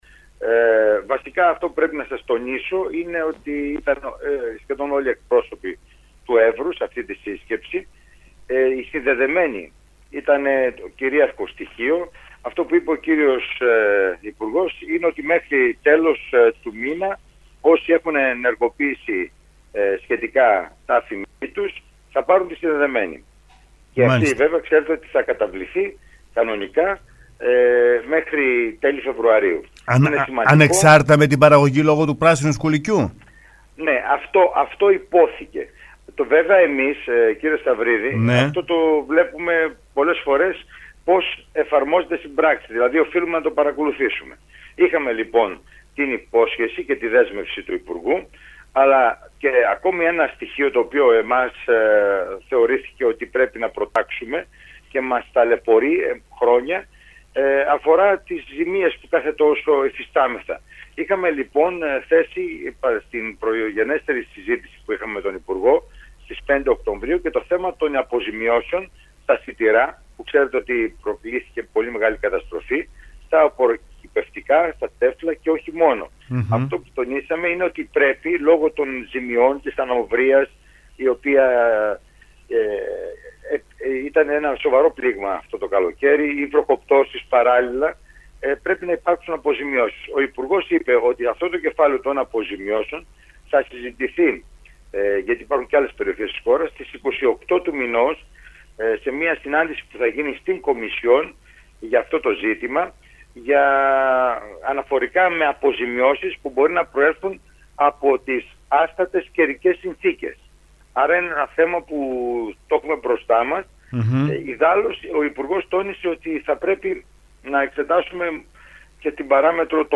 Στο ραδιόφωνο sferikos 99,3 φιλοξενήθηκε σήμερα το πρωί της Τρίτης ο αντιπεριφερειάρχης Έβρου Δημήτρης Πέτροβιτς και μίλησε